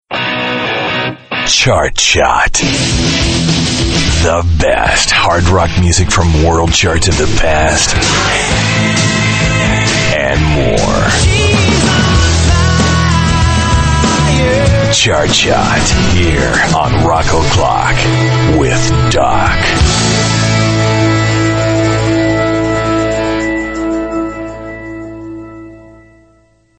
RADIO IMAGING / ROCK ALTERNATIVE /